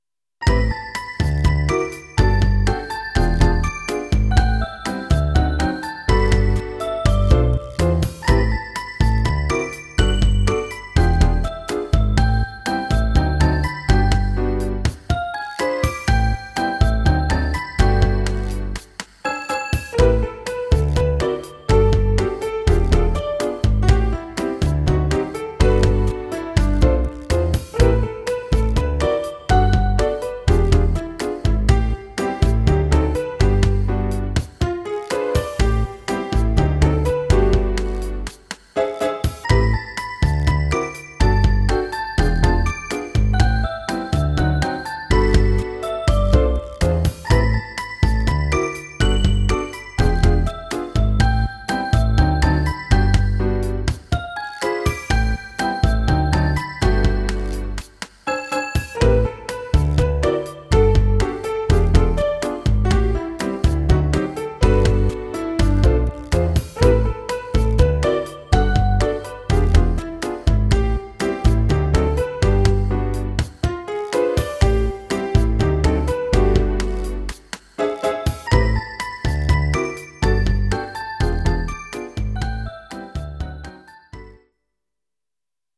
趣味でゲームBGMの適当な簡易アレンジを作って遊んでます。